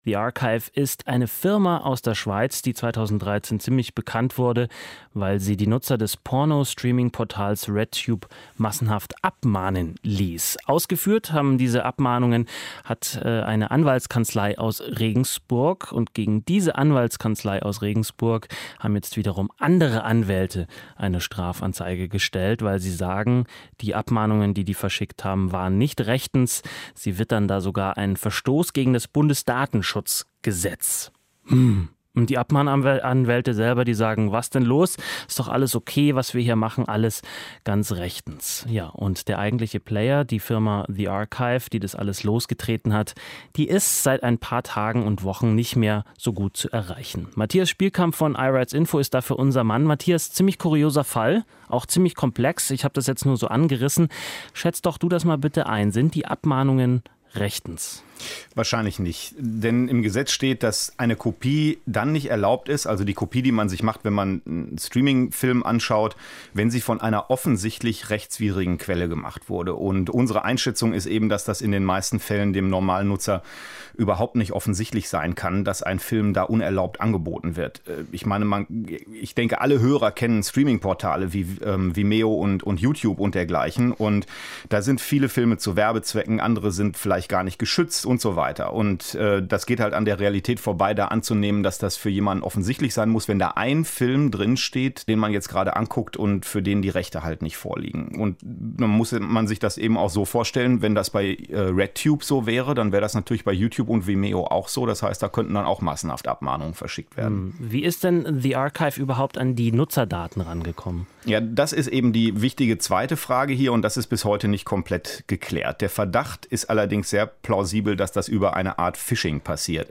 Daher veröffentlichen wir hier das Skript des Gesprächs, das einen aktuellen Überblick geben soll (der natürlich bei einem fünfminütigen Radiogespräch nicht erschöpfend sein kann).